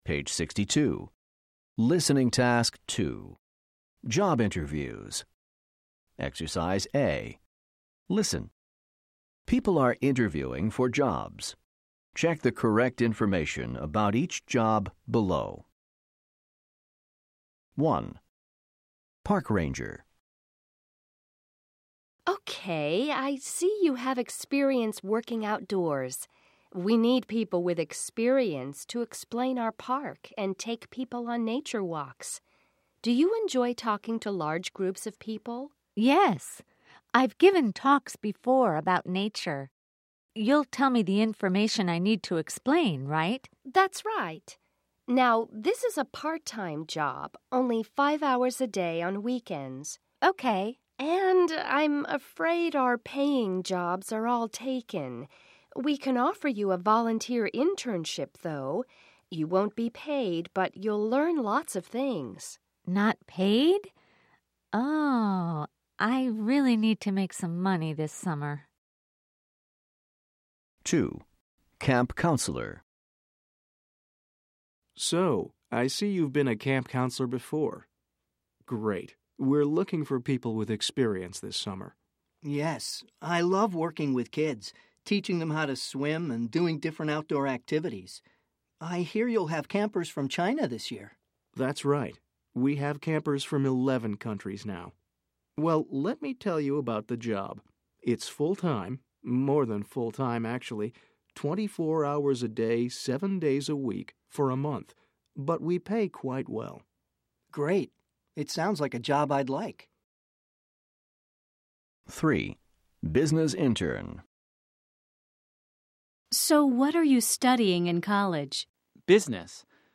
American English
Class Audio CDs include natural conversational recordings for the listening tasks in each unit, pronunciation practice, and expansion units containing authentic student interviews.